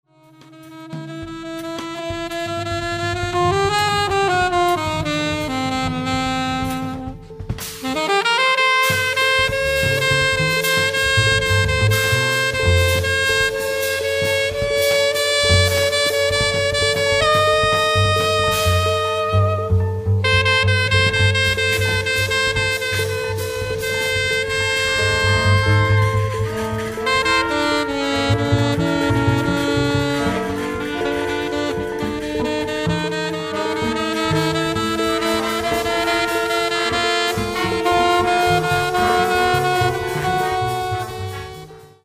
Mikrotonales und verminderte Quinten